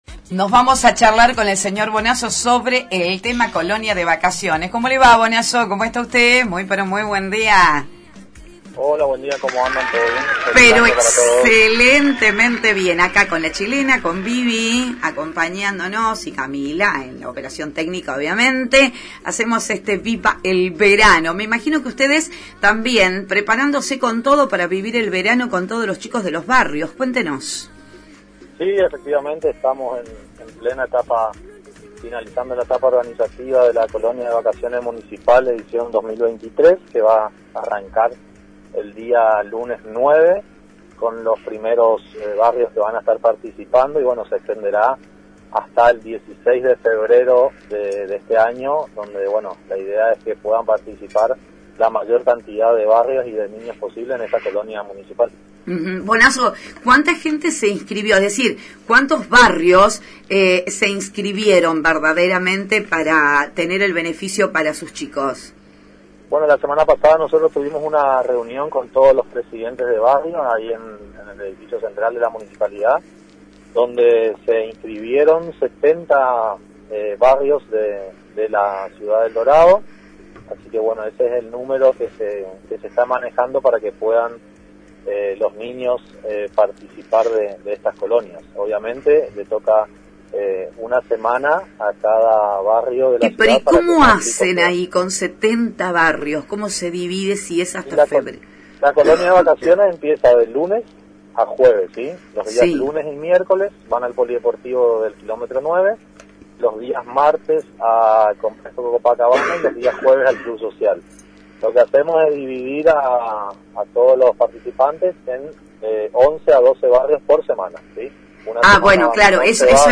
en diálogo con ANG y Multimedios Génesis